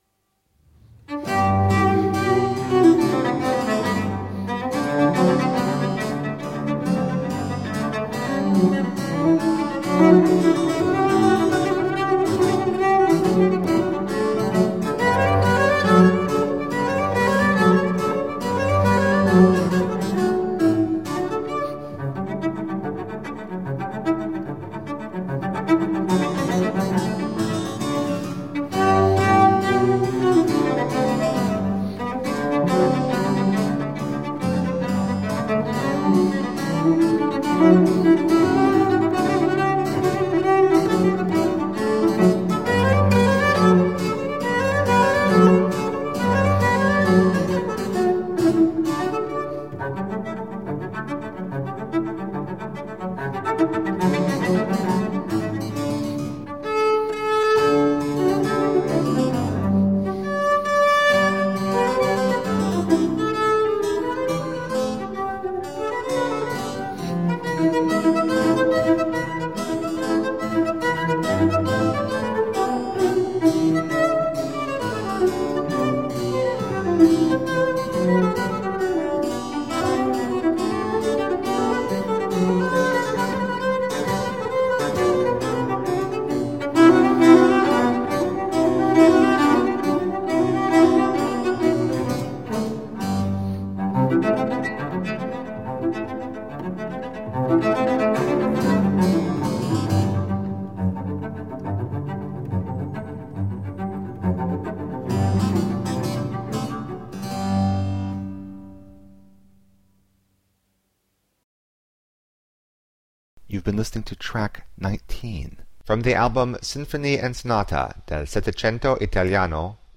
performed with virtuosity and lyricism
Classical, Baroque, Instrumental, Cello
Harpsichord